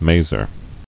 [ma·zer]